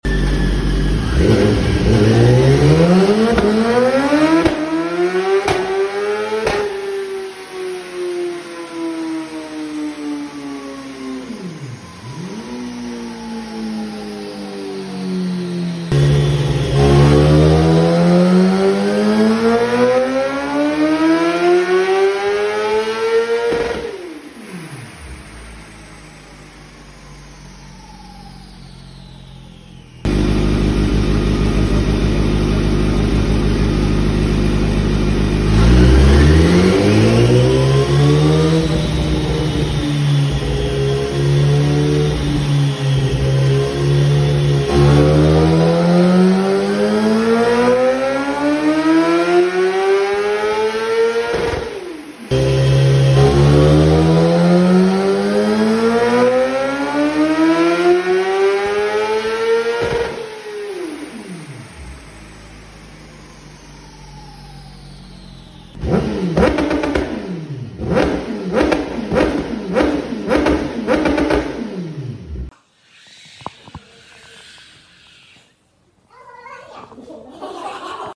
BMW S1000RR with a full Akrapovic Titanium exhaust came in for e tune check up and 2 step programming📈 Beautiful RR with lots of Carbon details 👌🏼 After we did the first check we noticed the bike was pulling really strong (already tuned by X) but here and there we could squeeze a bit more power and smoothen the tune more!